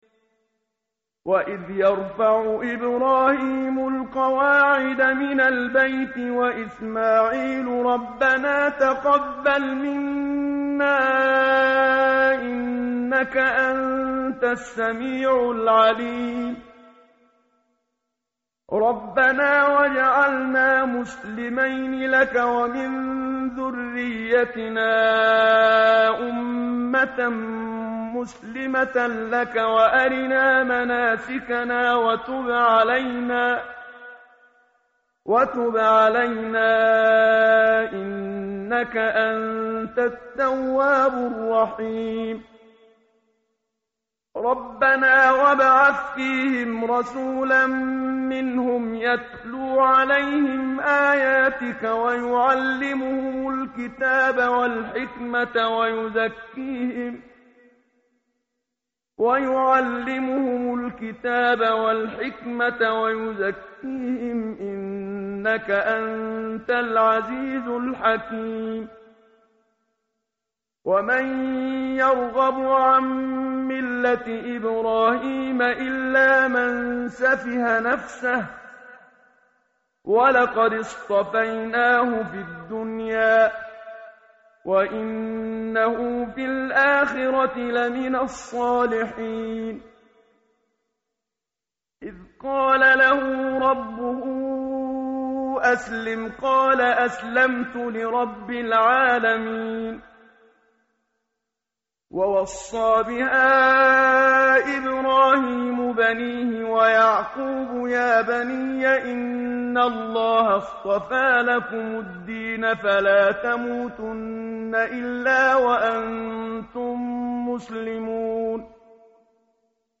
متن قرآن همراه باتلاوت قرآن و ترجمه
tartil_menshavi_page_020.mp3